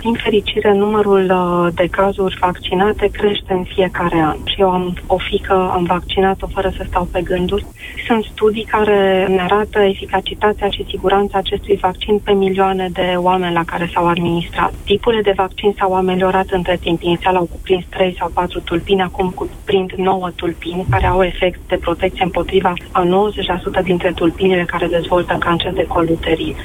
Vaccinarea anti HPV – Human Papilloma Virus – este opțională, în România, și gratuită. Vârsta optimă la care poate fi administrat acest vaccin este între 11 și 14 ani, dar și fetele care au trecut deja de această vârstă pot face vaccinul, a explicat, în emisiunea Deșteptarea de la Europa FM, medicul epidemiolog Andreea Moldovan, secretar de stat în Ministerul Sănătății: